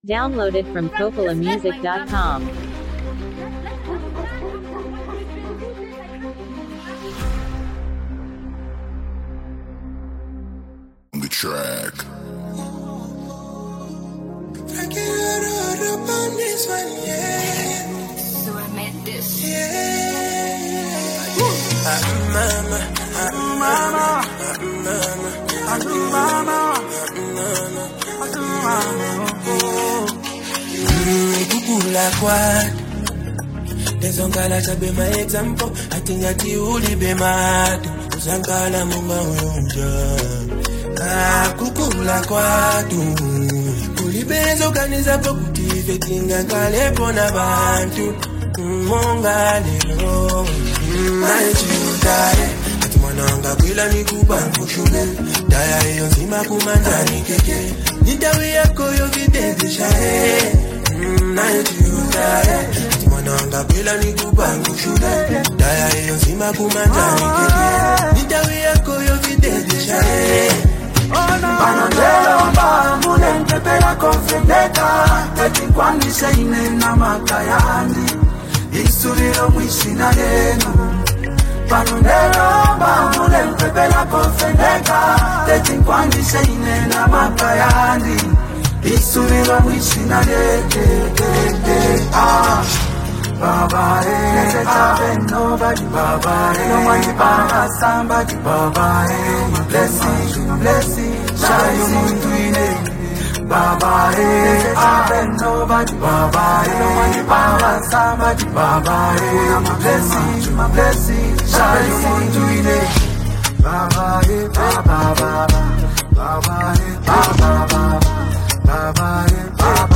a smooth and emotionally driven love song
heartfelt vocals convey vulnerability and sincerity
adds emotional depth and melodic richness